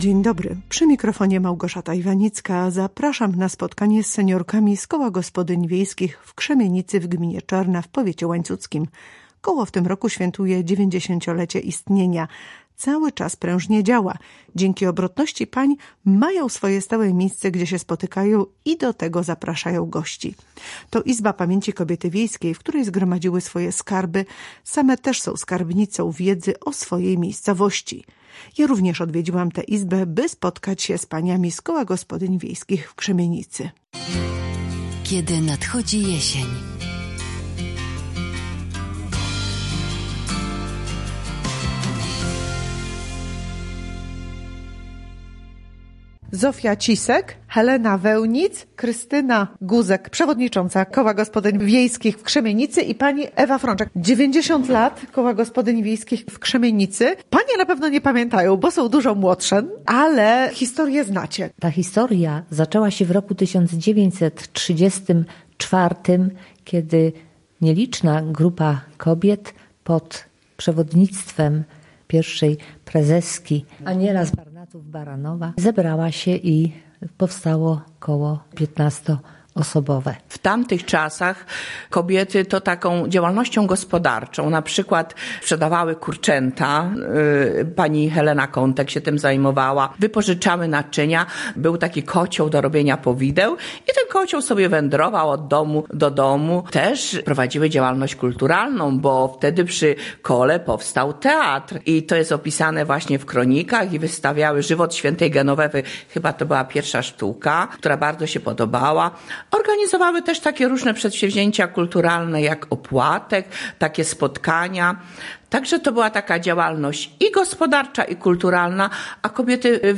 Zapraszam na spotkanie z seniorkami z Koła Gospodyń Wiejskich w Krzemienicy w Gminie Czarna w powiecie łańcuckim. Koło w tym roku świętuje 90-lecie istnienia.